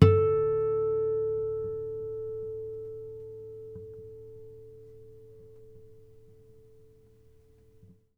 harmonic-03.wav